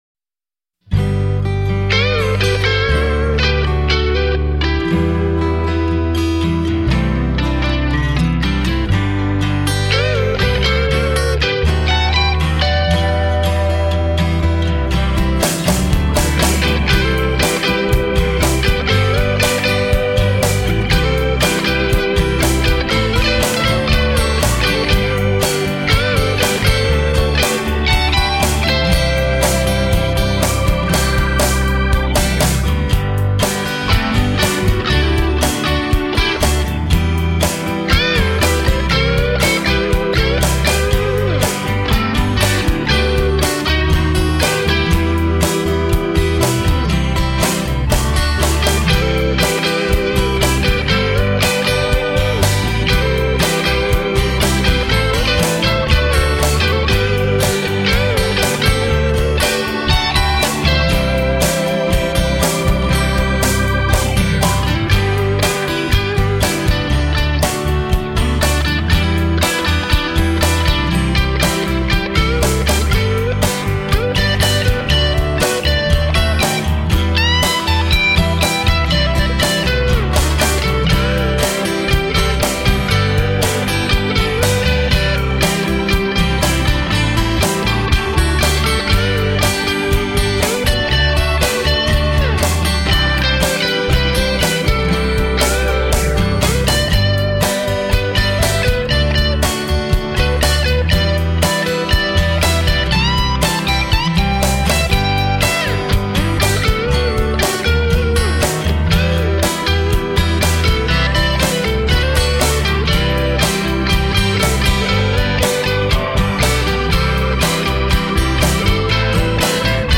Bluesy and jazzy guitar.
Hot, country-rock instrumentals.